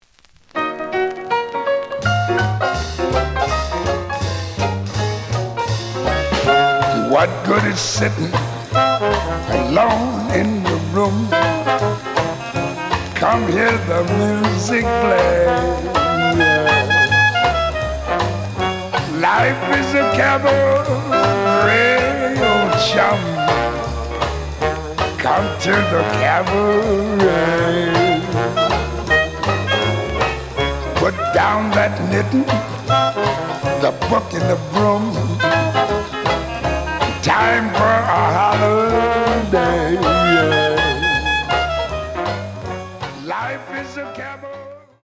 The audio sample is recorded from the actual item.
●Genre: Vocal Jazz